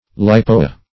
Leipoa \Lei*po"a\ (l[-i]*p[=o]"[.a]), n. [NL.] (Zool.)